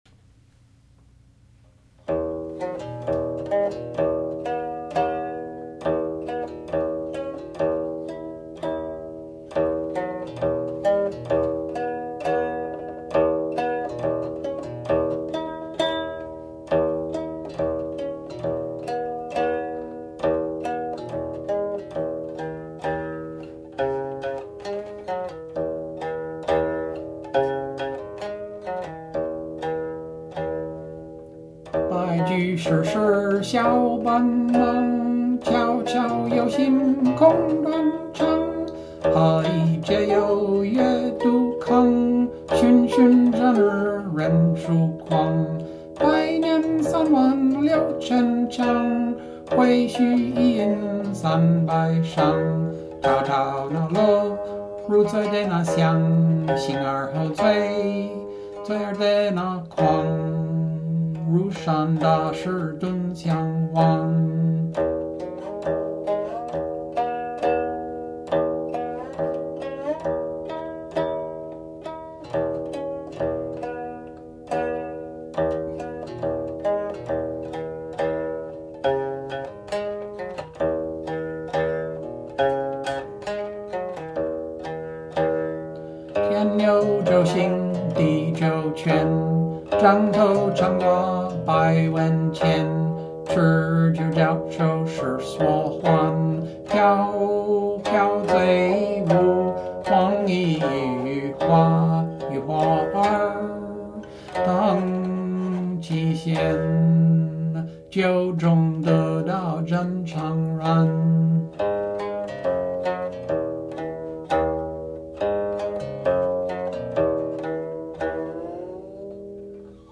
Wine Mad : Qin song version
Seven sections, titled, plus a coda (compare the 1425 version) 29 (The timings below refer to my recordings of the combined 1425 and 1589 versions of Jiu Kuang;